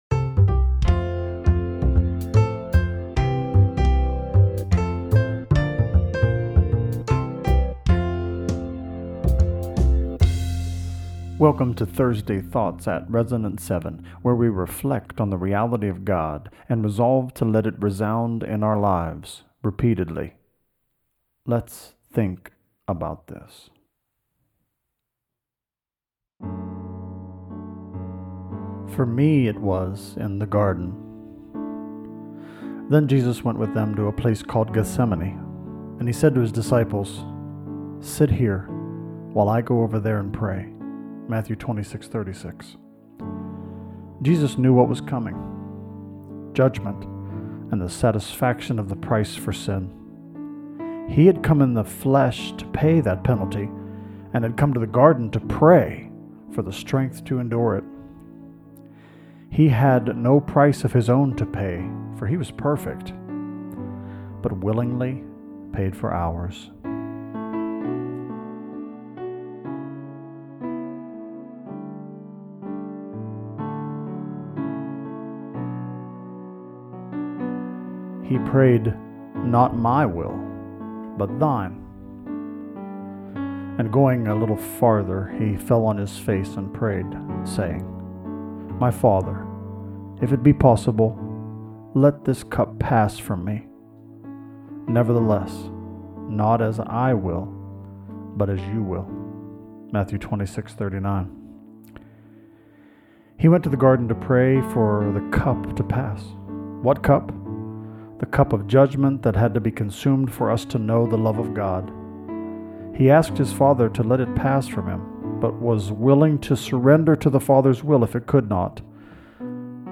Listen to this modern version of this hymn as you prepare to ponder the lyrics.